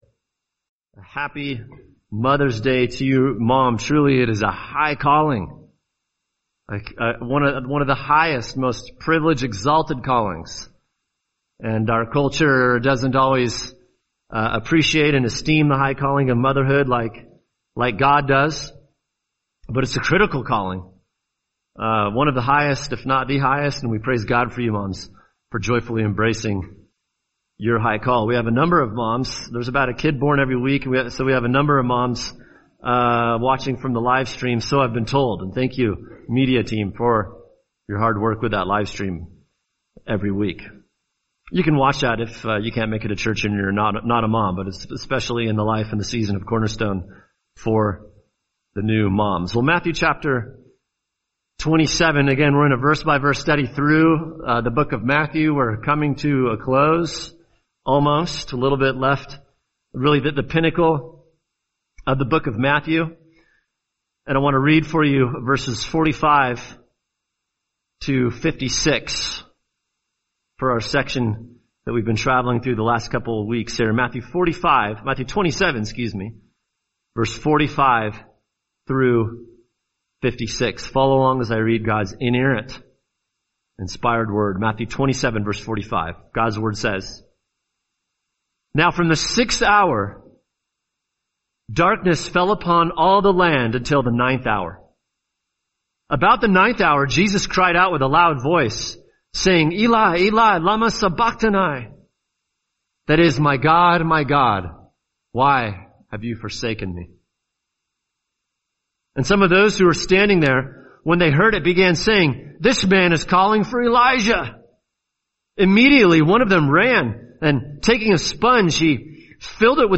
[sermon] Matthew 27:51-56 What God Thinks About The Cross – Part 2 | Cornerstone Church - Jackson Hole